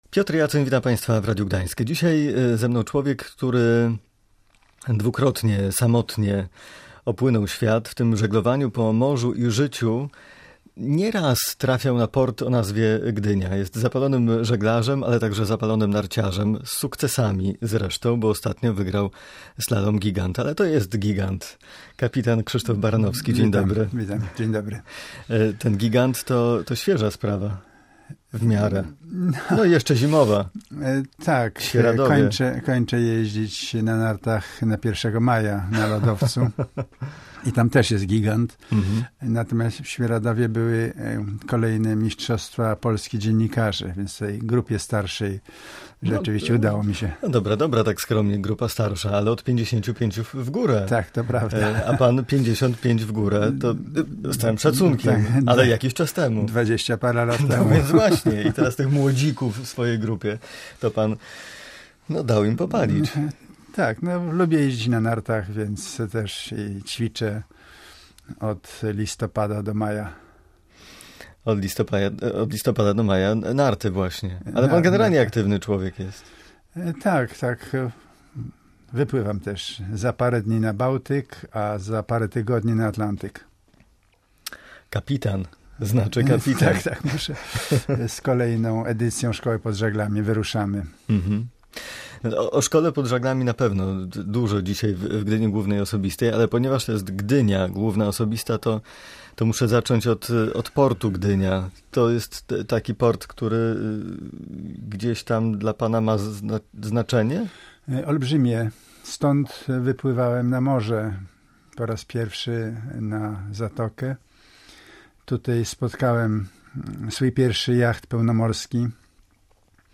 W rozmowie z Piotrem Jaconiem opowiadał także o swoich początkach z żeglarstwem i trudnych egzaminach na kapitana.